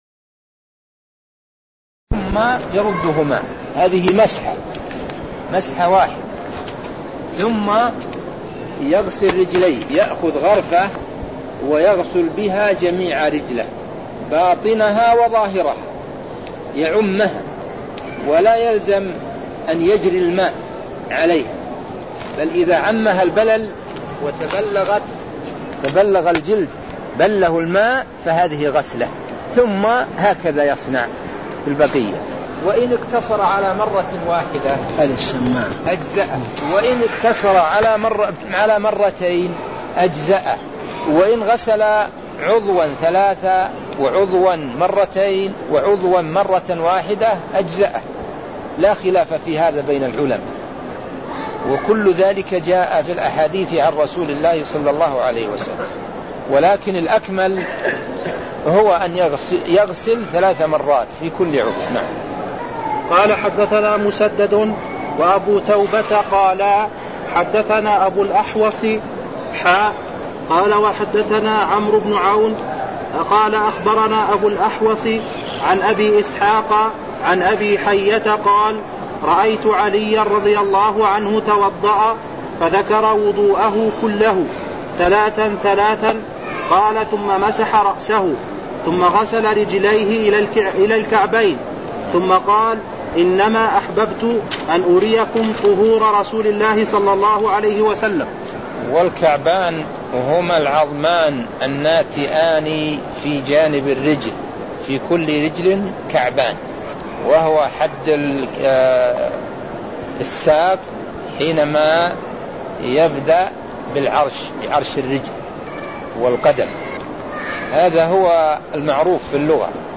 المكان: المسجد النبوي الشيخ: عبدالله الغنيمان عبدالله الغنيمان باب صفة وضوء النبي صلى الله عليه وسلم (02)